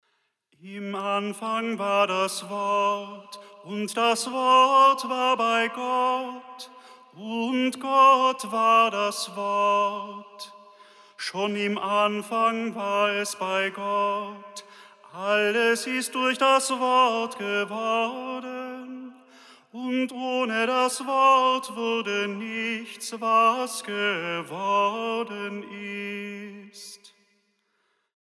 kirchlicher Gesänge und Gebete